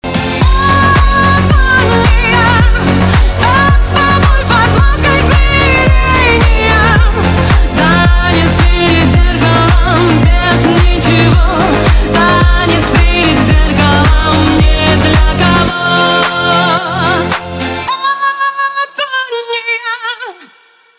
Клубные
Танцевальные » Ремиксы